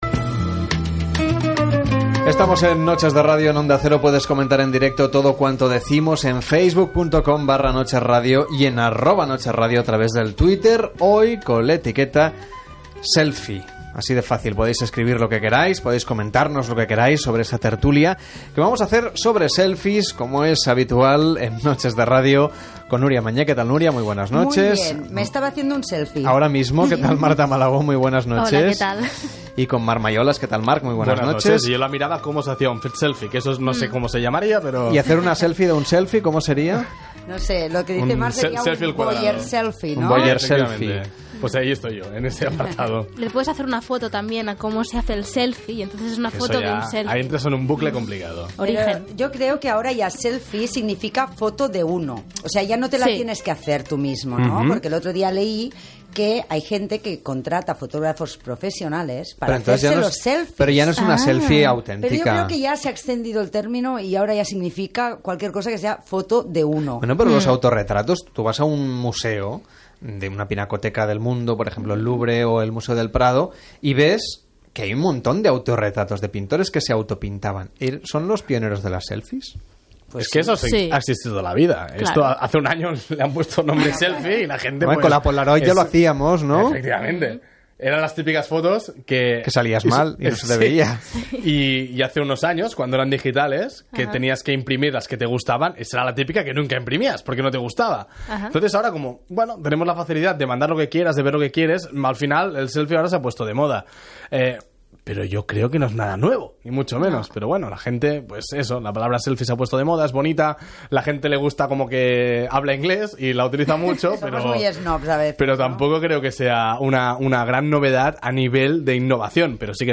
678c5dc735808e33a5c3c39252ba981bccf62726.mp3 Títol Onda Cero Radio Emissora Onda Cero Barcelona Cadena Onda Cero Radio Titularitat Privada estatal Nom programa Noches de radio Descripció Identificació del programa i formes de participar-hi. Tertúlia